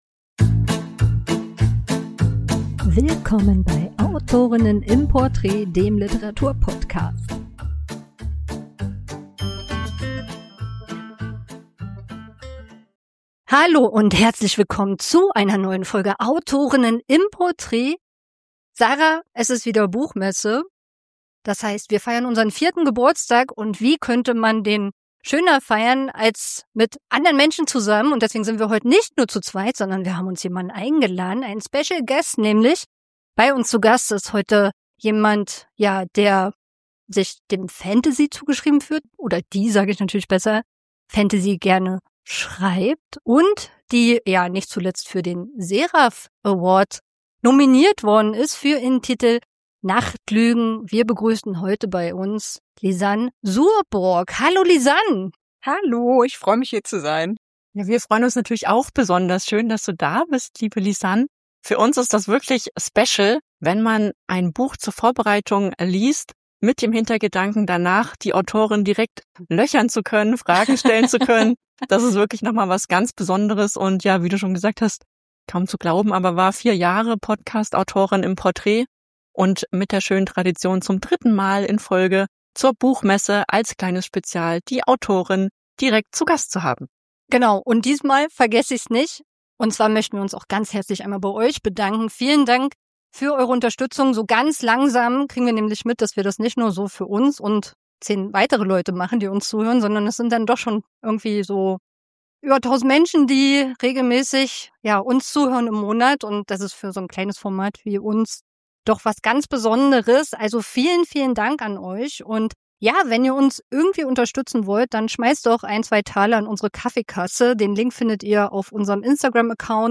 Folgt uns, in unserer Spezial-Folge von "Autorinnen im Porträt", in das Reich der Albträume, denn zur Leipziger Buchmesse haben wir uns wieder etwas besonderes einfallen lassen und uns eine Autorin zum persönlichen Gespräch eingeladen.